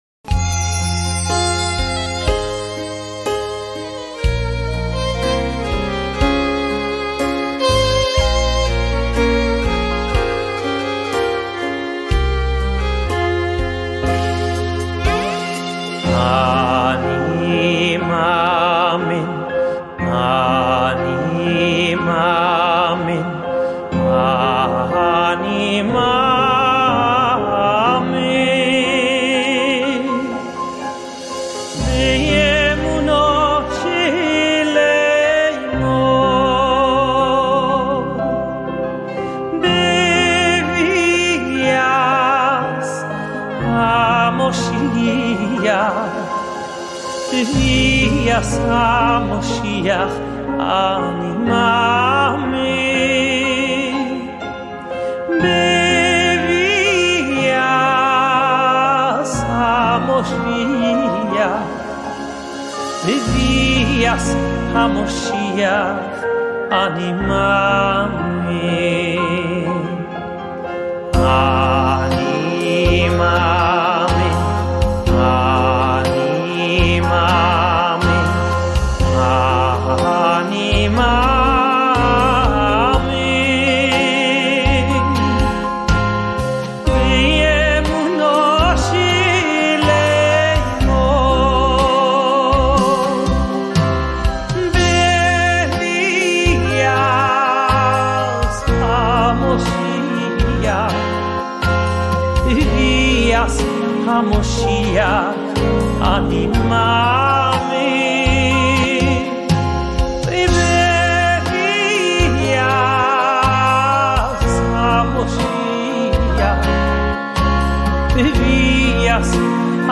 Le douzième article dit, "Je crois d'une foi parfaite que le Messie arrivera, et bien qu'il tarde , je crois en sa venue"  . C'est sur cet air que certaines communautés 'hassidique  pendant la Shoah ont marché vers les chambres à Gaz.
25_01_AniMaamin with Shulem Lemmer.mp3